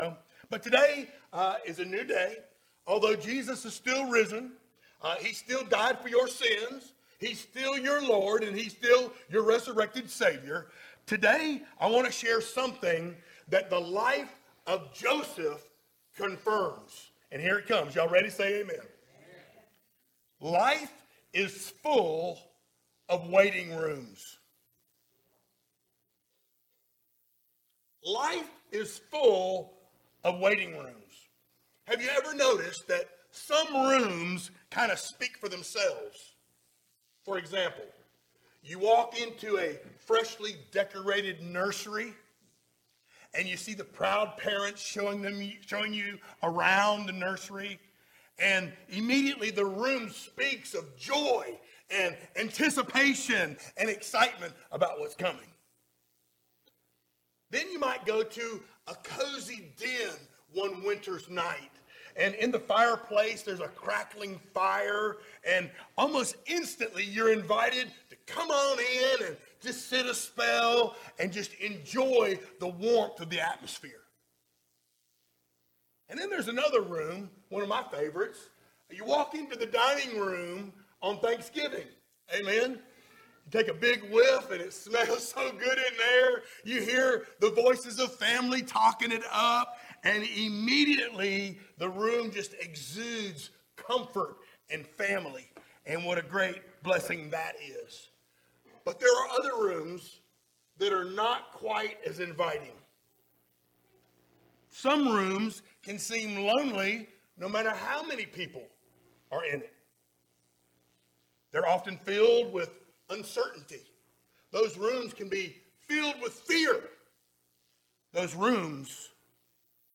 Series: sermons
Genesis 41:1-40 Service Type: Sunday Morning Download Files Notes Topics